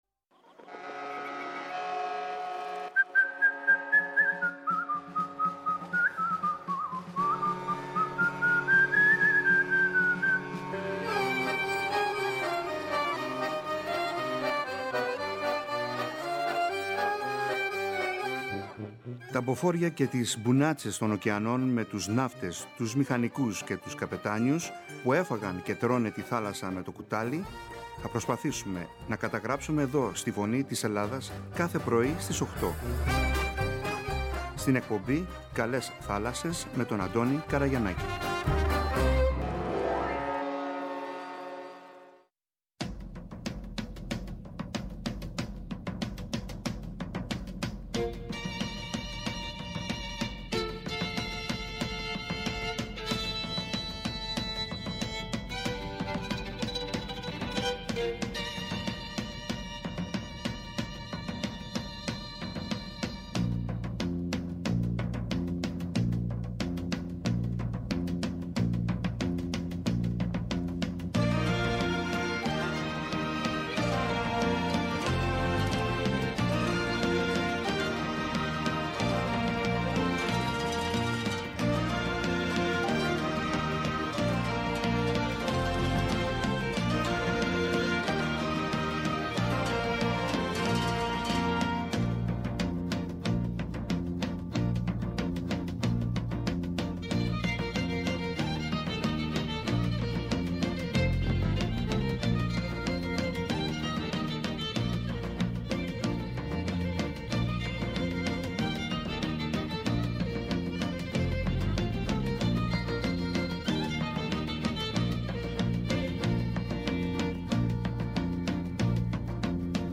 Είναι πιο πολύ ανθρωποκεντρική εκπομπή για τον ναυτικό, με τα θέματα του, τη ναυτιλία, τραγούδια, ιστορίες, συναισθήματα, σκέψεις, και άλλα πολλά όπως π.χ η γυναίκα εργαζόμενη στη ναυτιλία, η γυναίκα ναυτικού, είδη καραβιών, ιστορίες ναυτικών οικογενειών, ιστορίες ναυτικών, επικοινωνία μέσω του ραδιοφώνου, ναυτικές ορολογίες, τραγούδια, ποίηση, πεζογραφία για τη θάλασσα, εξαρτήματα του πλοίου, ήδη καραβιών ναυτικά επαγγέλματα κλπ κλπ Ήδη έχουν ανταποκριθεί αρκετοί, παλιοί και εν ενεργεία καπετάνιοι και μίλησαν με μεγάλη χαρά και συγκίνηση για την ζωή – καριέρα τους στην θάλασσα και τι σημαίνει για αυτούς.